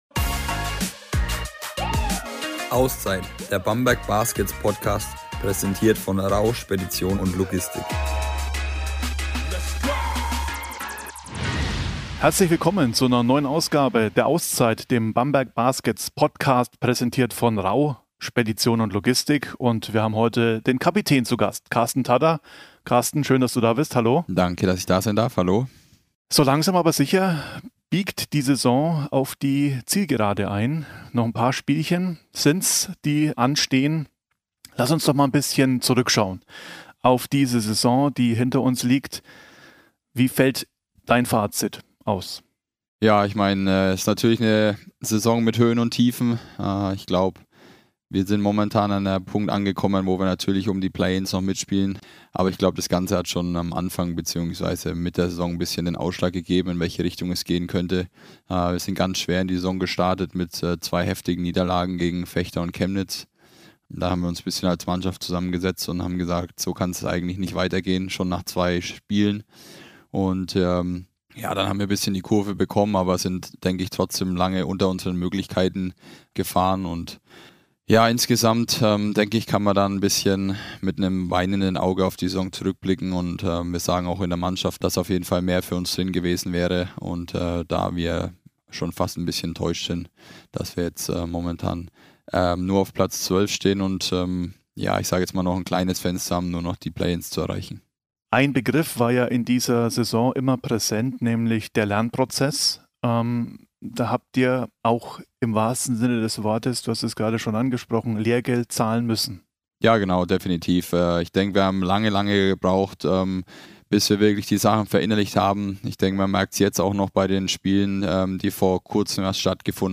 Gemeinsam mit Kapitän Karsten Tadda blicken wir schon einmal zurück auf die Saison und suchen die sowohl positiven als auch negativen Überraschungen dieser Spielzeit. Wir schauen unter anderem aber auch voraus und wagen eine Prognose, welche Teams es in die Playoffs schaffen, wer letztlich ganz oben steht und wer wahrscheinlich absteigen wird.